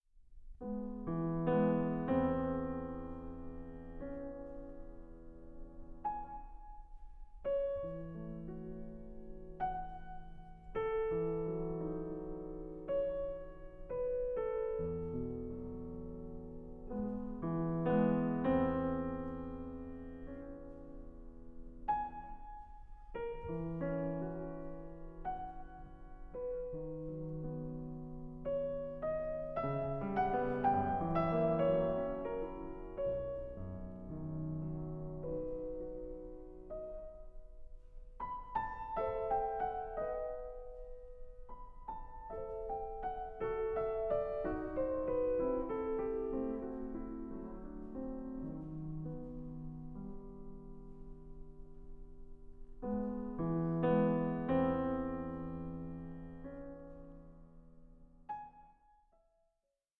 piano
By turns placid, sparse, restive and impassioned